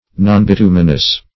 Nonbituminous \Non`bi*tu"mi*nous\